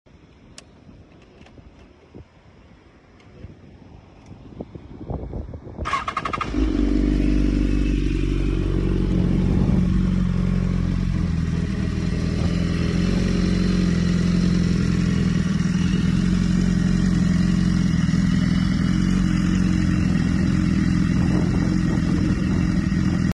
My new bike bmw s1000RR sound effects free download